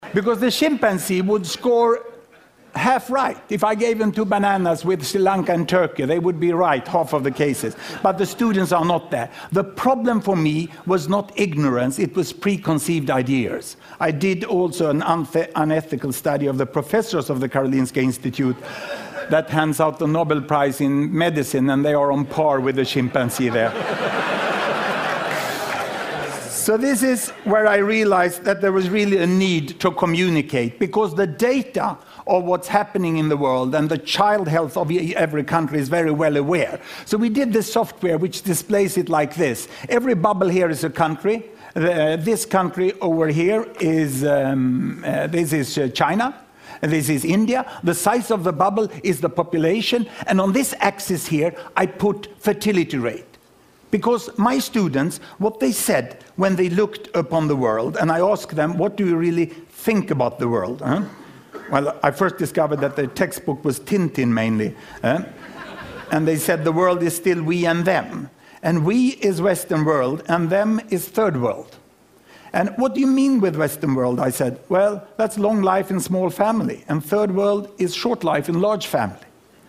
TED演讲：用前所未有的好方法诠释数字统计(2) 听力文件下载—在线英语听力室